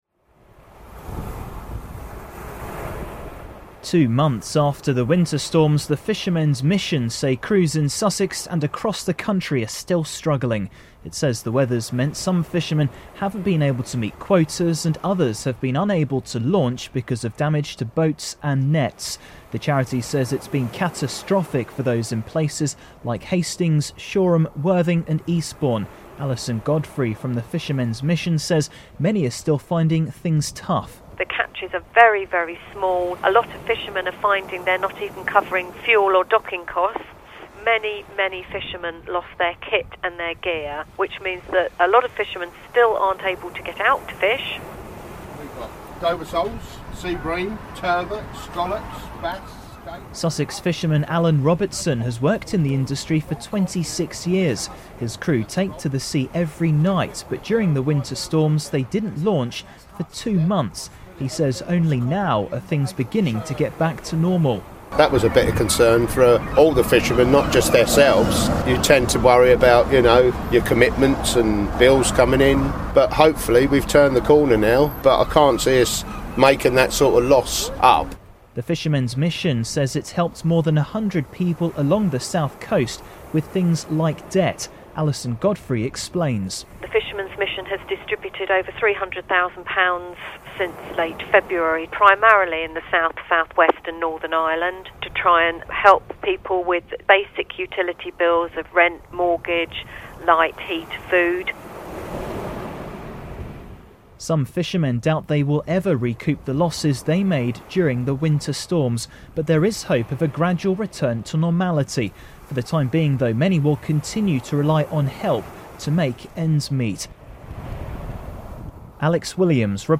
SPECIAL REPORT: Fishermen 'still struggling' after winter storms